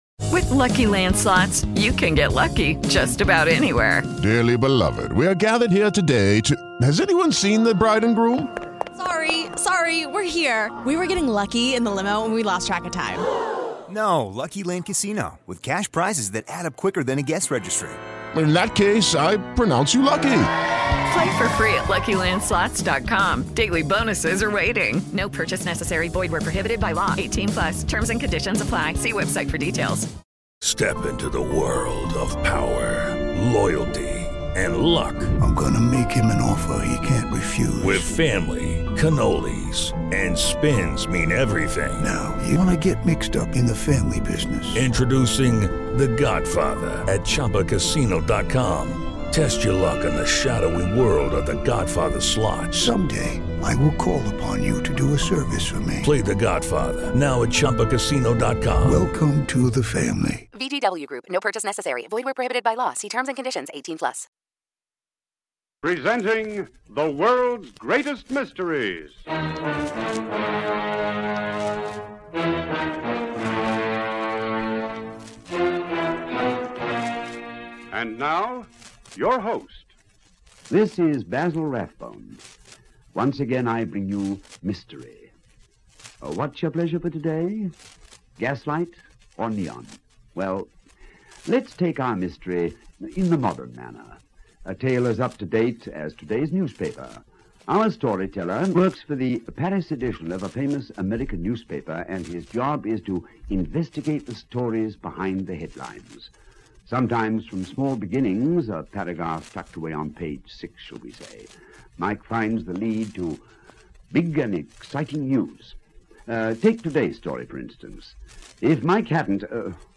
Europe Confidential, a classic from the golden age of radio!